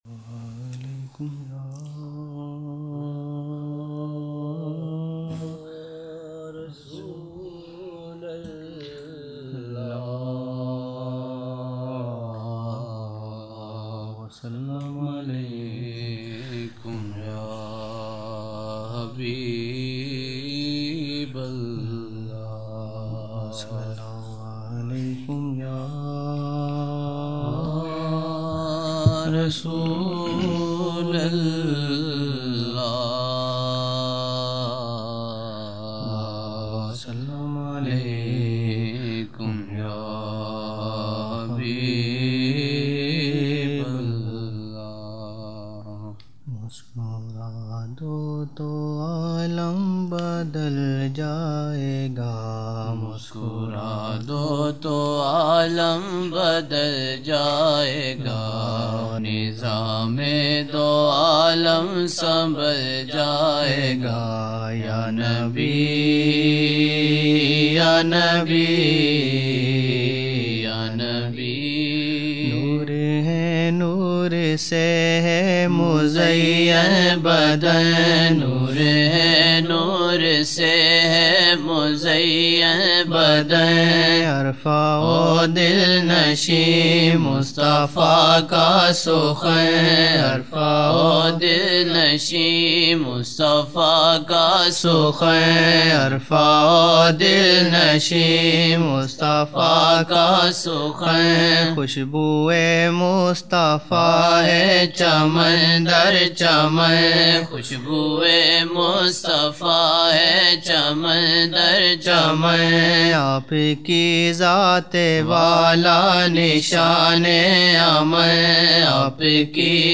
20 November 1999 - Maghrib mehfil (12 Shaban 1420)
Dua Mubarak, taleem shareef (Allah Ghaffar hai, Allah ka pyar) Naat shareef: